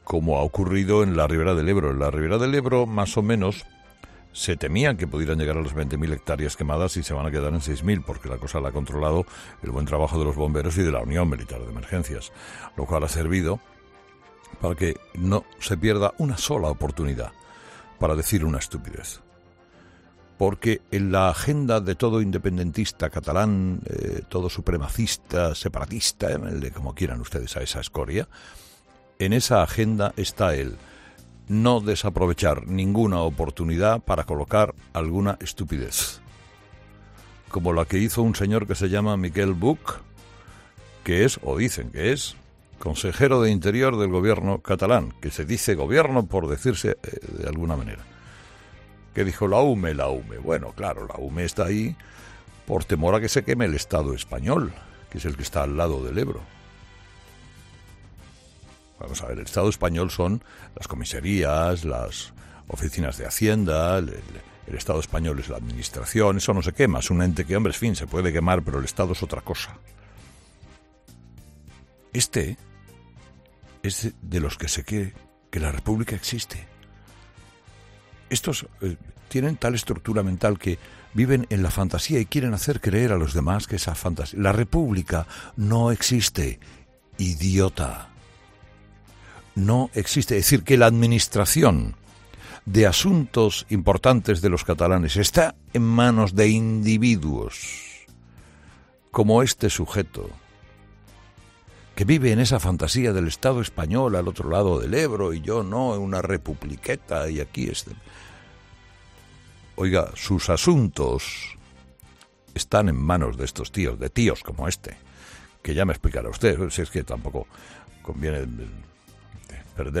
A estas declaraciones ha querido contestar Carlos Herrera en sus monólogos de las 7 y 8 de la mañana.